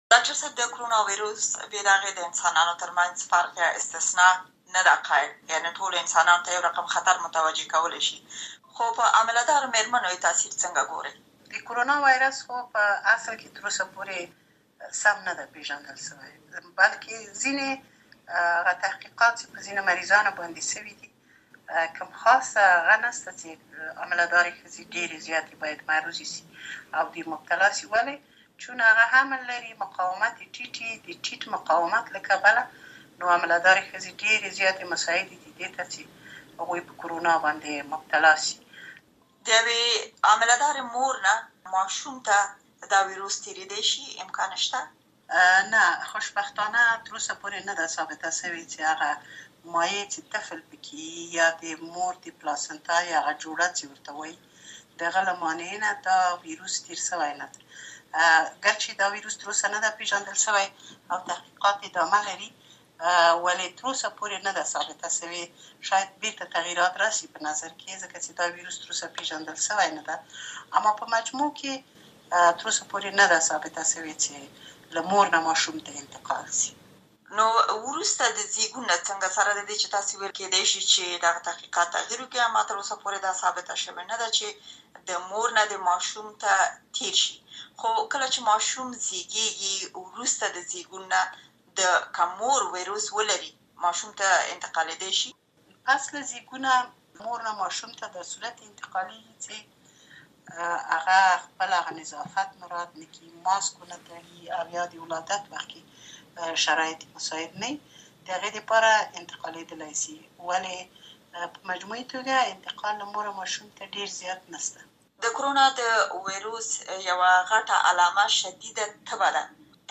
امریکا غږ سره د ډاکتر نسرین اوریا خیل مرکه